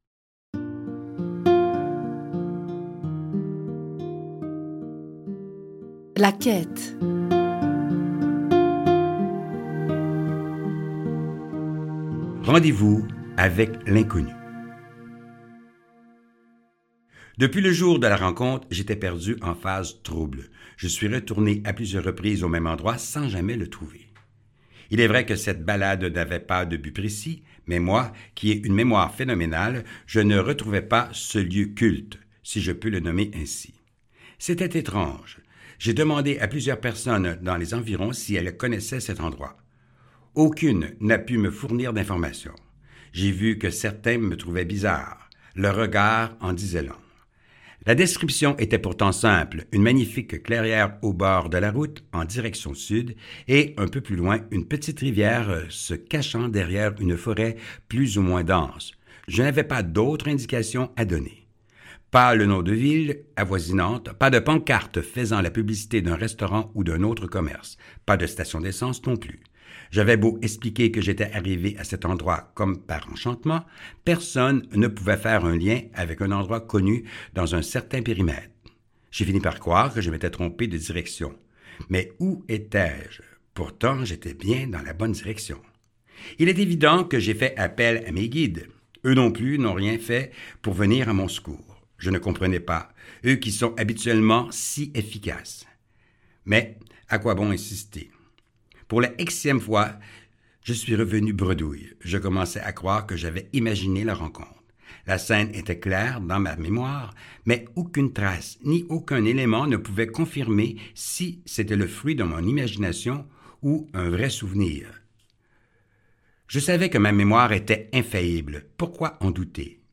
Diffusion distribution ebook et livre audio - Catalogue livres numériques
Tout au long de la lecture, lorsque vous entendrez la phrase, "Presse le bouton !" vous m'entendrez alors vous dire un message extraordinaire.